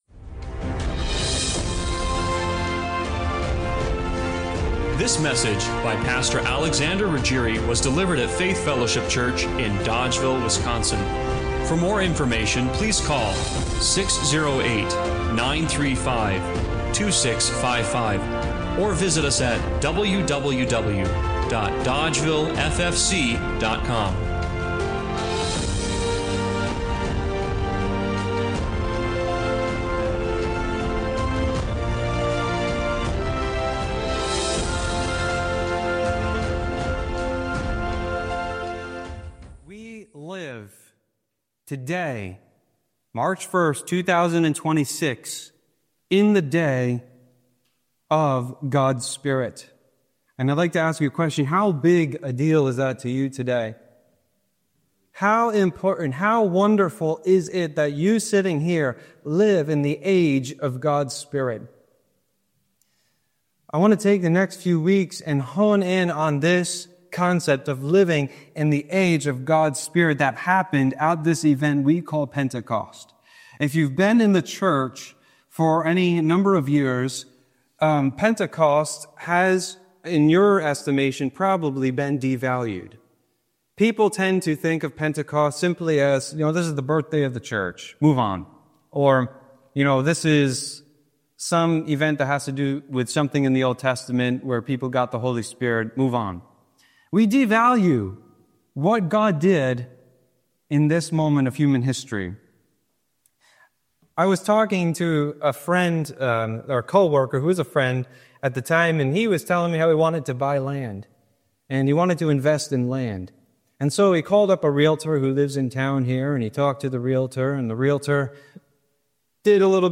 Acts 2:1 Service Type: Sunday Morning Worship What if the moment you’re living in is far bigger than you’ve ever imagined?